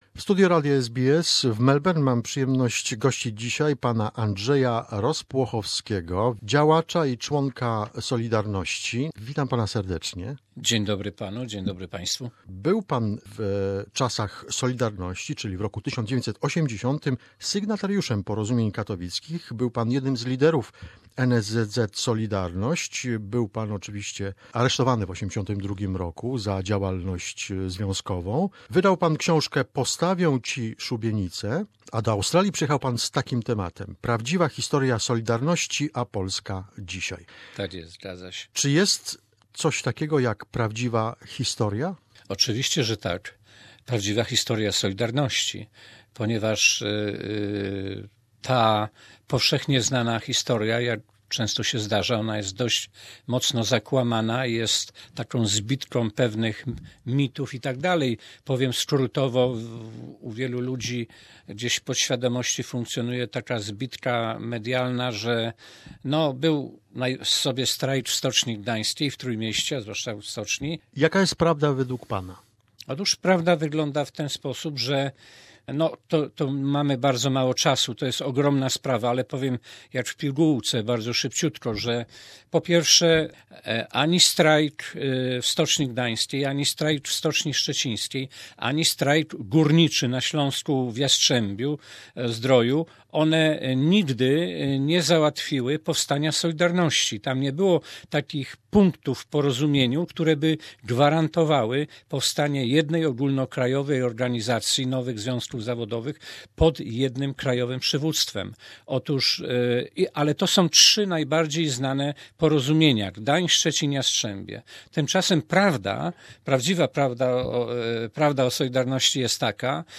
This is a part 1 of the interview.